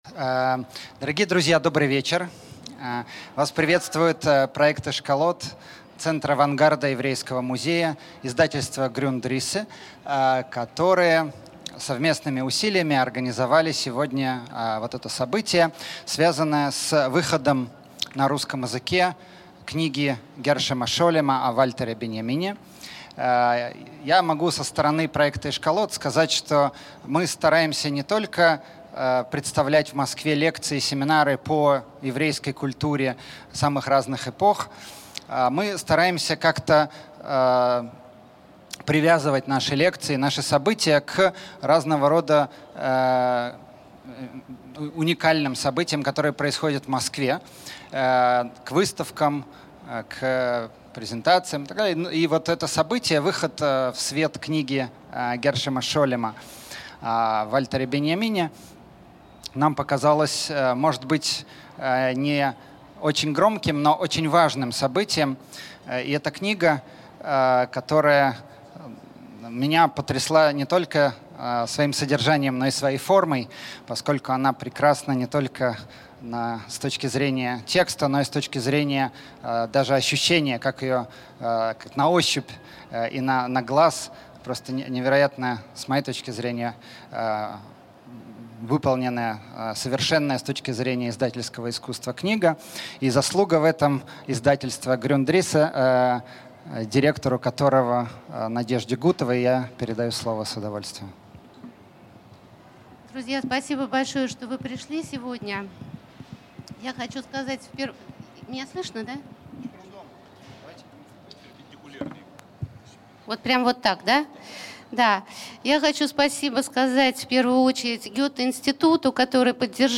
Аудиокнига Гершом Шолем и Вальтер Беньямин | Библиотека аудиокниг